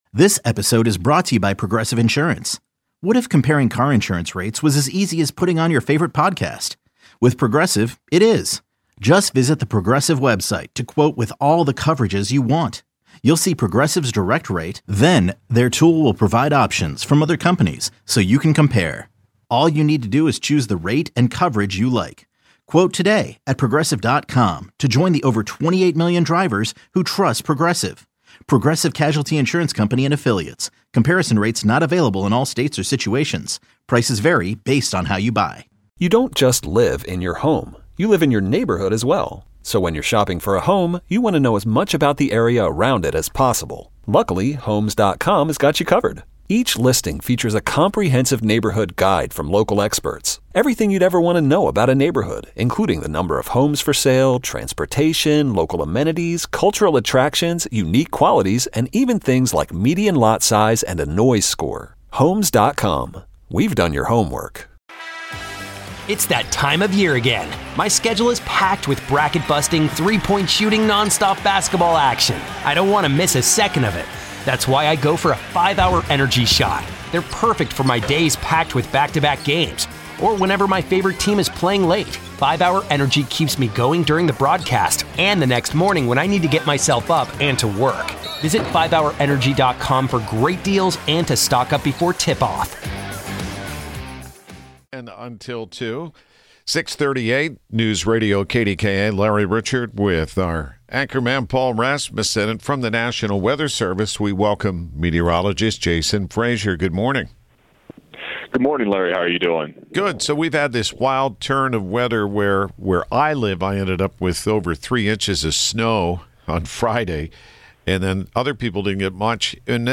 calls in to talk about the dirt found on cars across the country and talk about the warm weather coming up!